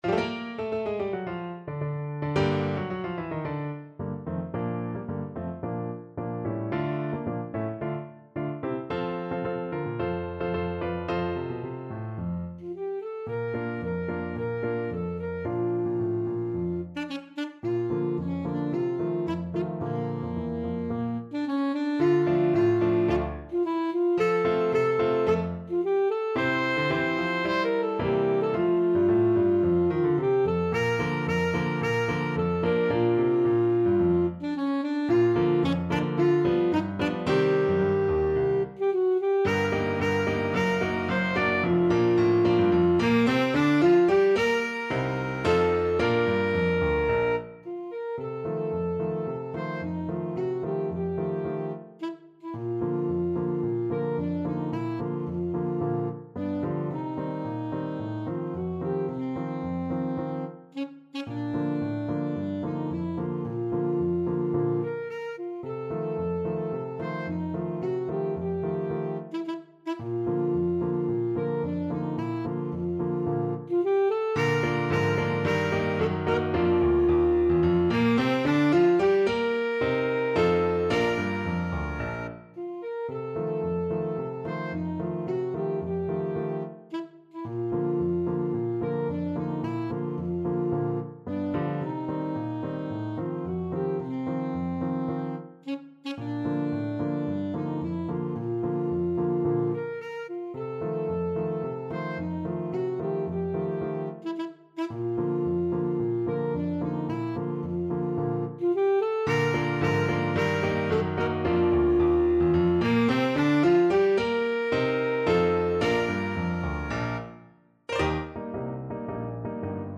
Alto Saxophone
2/2 (View more 2/2 Music)
Bb4-D6
Classical (View more Classical Saxophone Music)